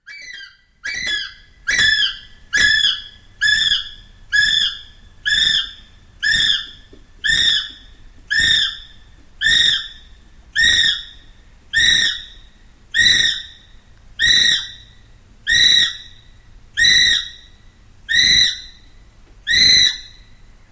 Звуки птицы киви
На этой странице собраны звуки птицы киви — уникальной нелетающей птицы из Новой Зеландии. Вы можете слушать онлайн или скачать её голоса в формате mp3: от нежных щебетаний до характерных криков.